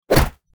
hit05.mp3